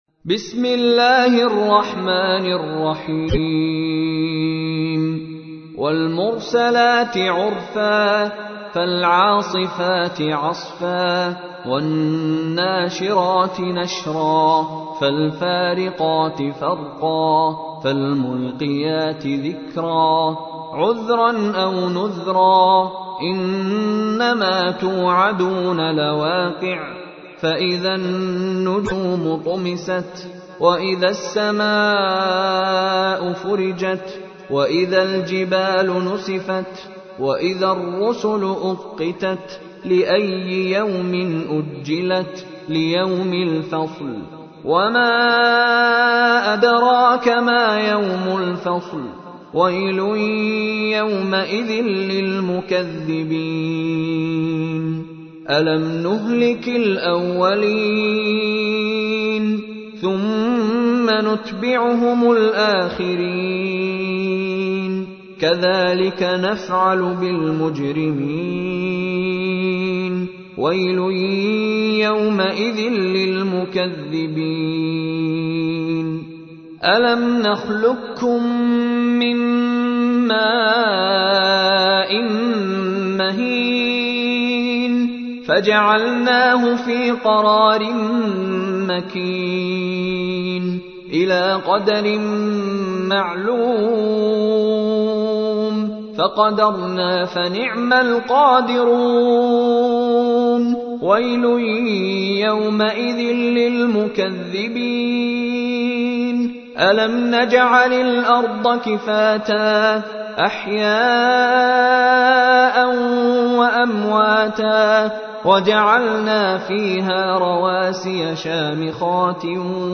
تحميل : 77. سورة المرسلات / القارئ مشاري راشد العفاسي / القرآن الكريم / موقع يا حسين